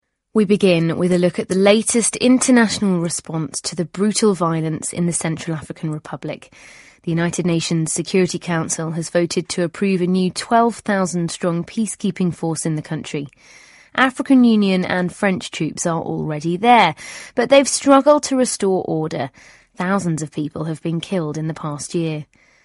【英音模仿秀】联合国安理会将向中非派遣维和部队 听力文件下载—在线英语听力室